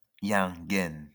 Hienghène (French pronunciation: [jɛ̃ɡɛn]